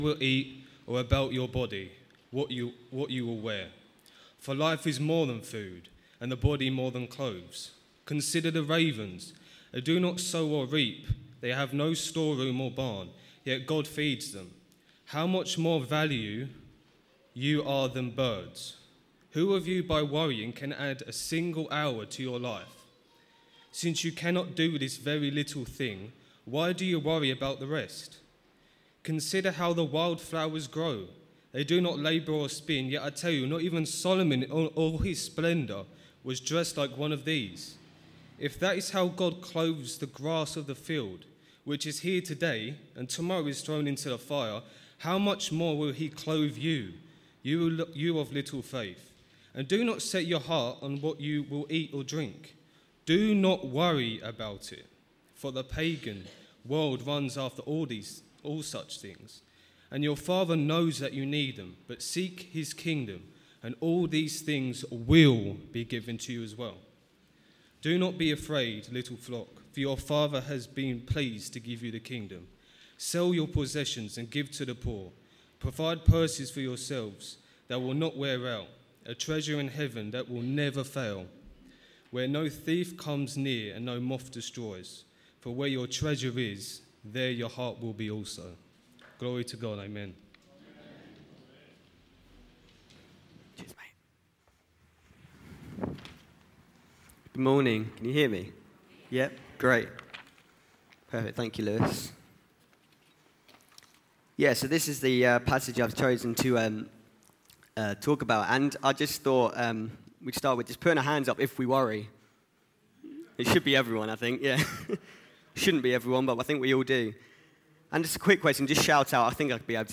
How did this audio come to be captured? A message from the series "All Age Signed Service."